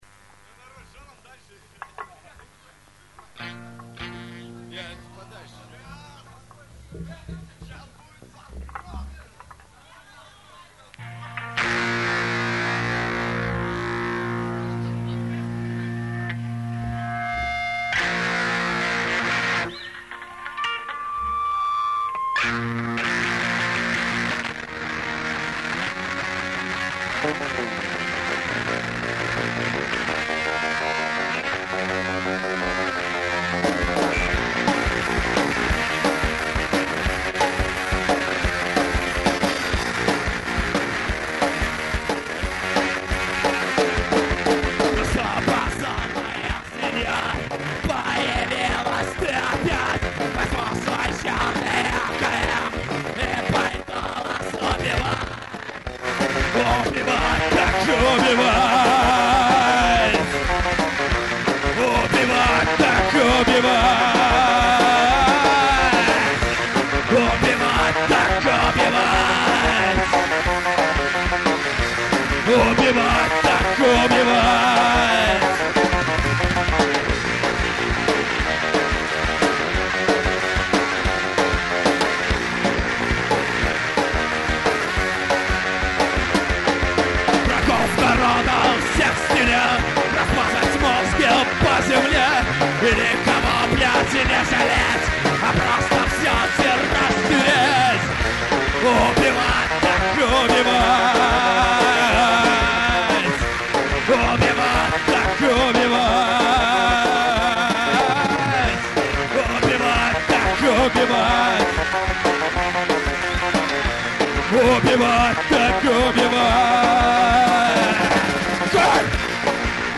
Концерт 18.02.00 в ЦеЛКе в Двинске.